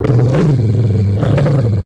bdog_groan_1.ogg